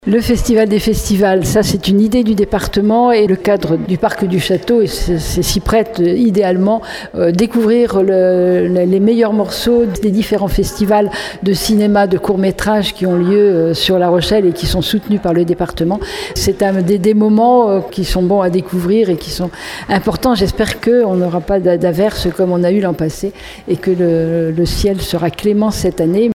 Catherine Desprez, maire de Surgères et vice-présidente du Département :